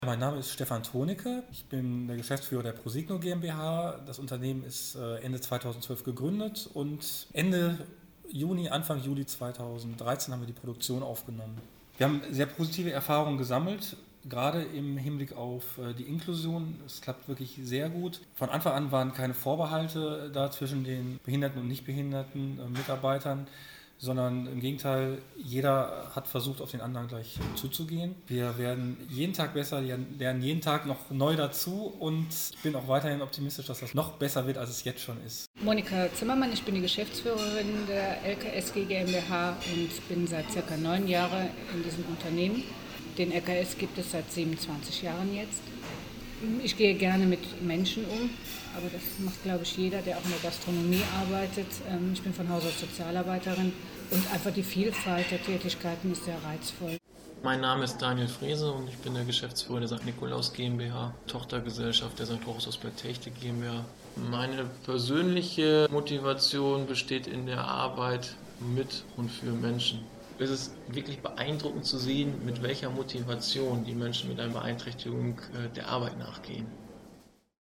Anlage 1: Audio-Collage (Erfahrungen dreier Integrationsunternehmer).mp3